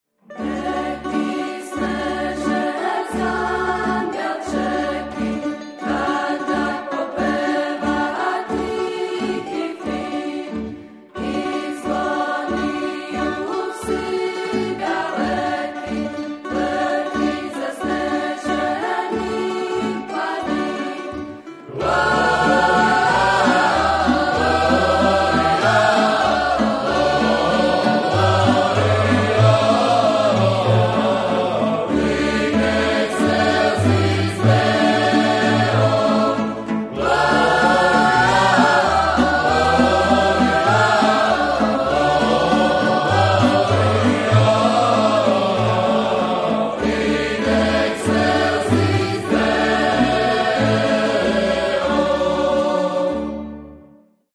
Drugi nosač zvuka KUD-a “Marof” sadrži trinaest popularnih božićnih skladbi.
Mehki snežek (francuska narodna)